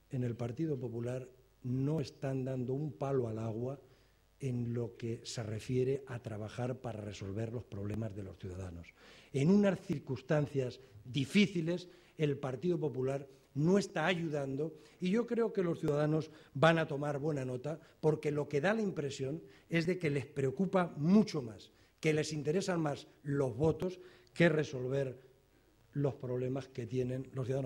Acto Público de los socialistas de Albacete con el Vicesecretario General y ministro de Fomento